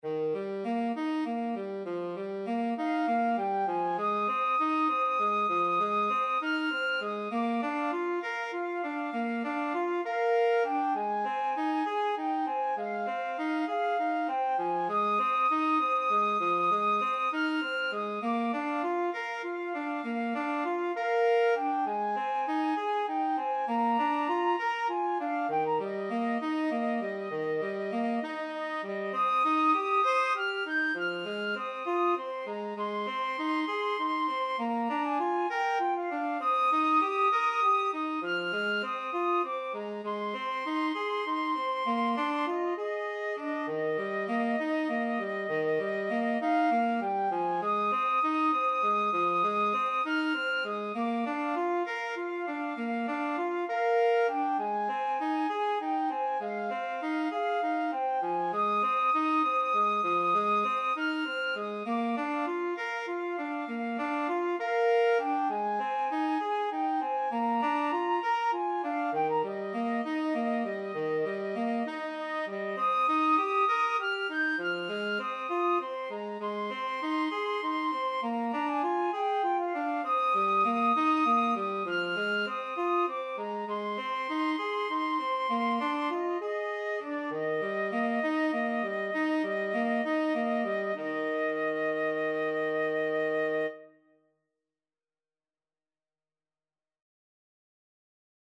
FluteTenor Saxophone
6/8 (View more 6/8 Music)